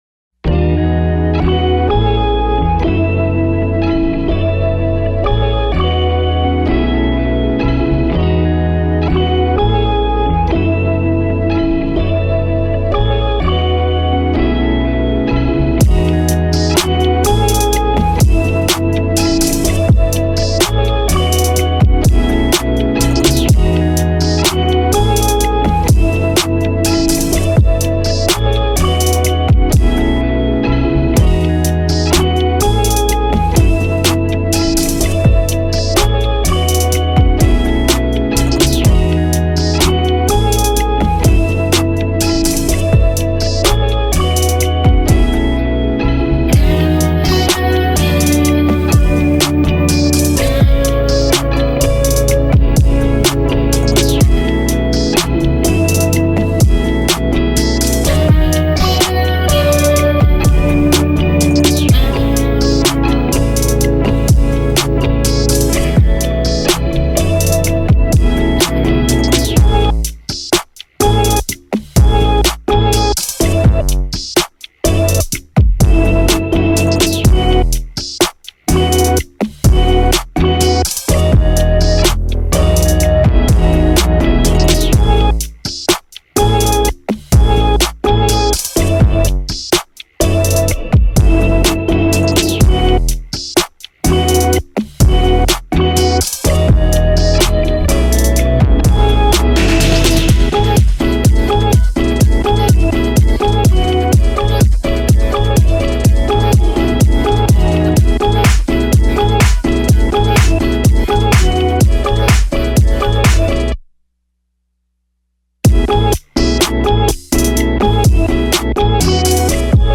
K-Pop Instrumental